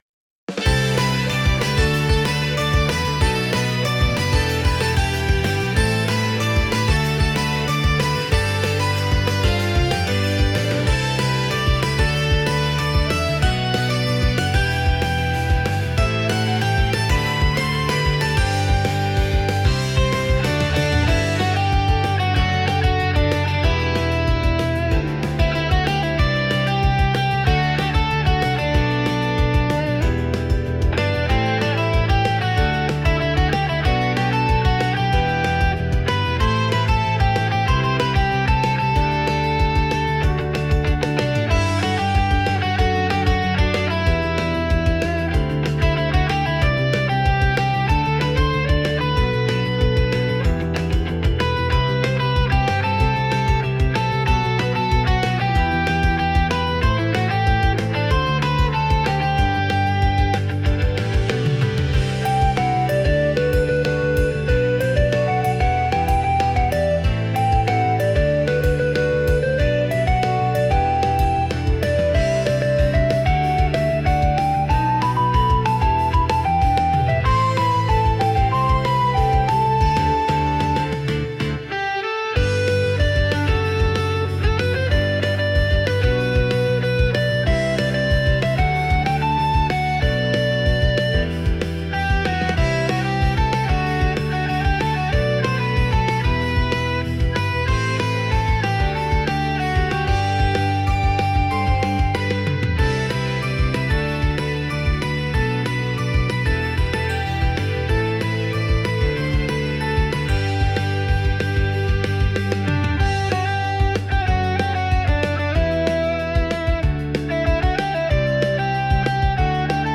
の姉妹曲となる、前向きでキラキラしたアイドル風ポップBGMです。